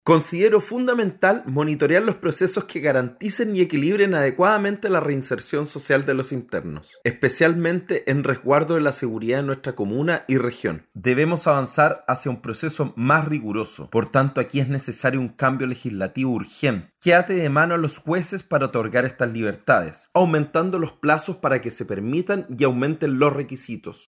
Por su parte, el presidente de la Comisión de Seguridad Ciudadana de Valparaíso, Miguel Vergara, manifestó preocupación por la alta cantidad de postulaciones, señalando que este tipo de procesos debe desarrollarse con especial rigurosidad, considerando tanto en la reinserción como en la percepción de seguridad de la comunidad.